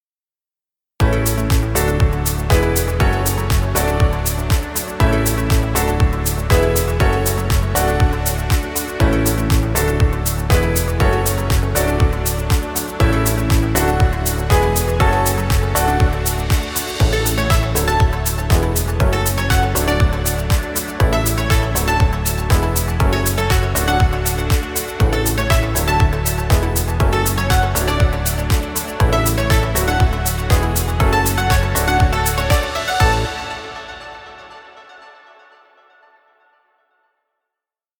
Royalty Free Music.